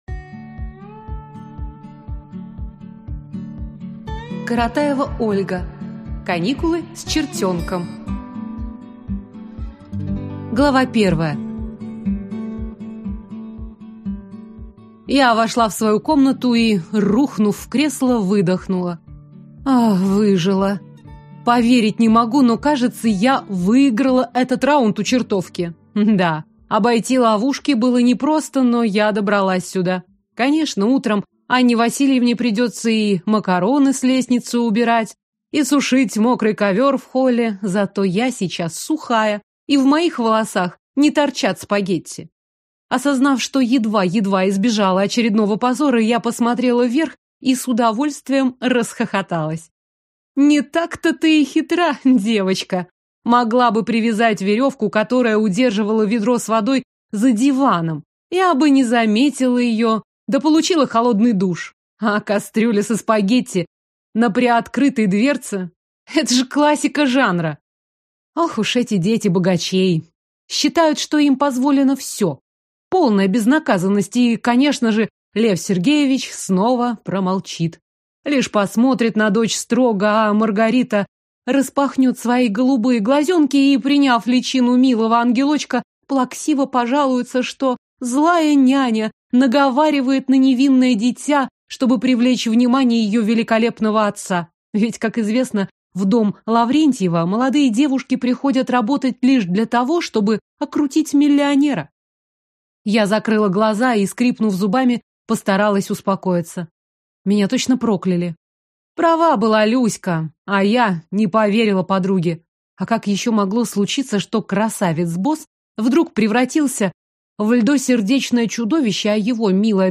Аудиокнига Каникулы с чертёнком | Библиотека аудиокниг